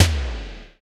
TOM XC.TOM06.wav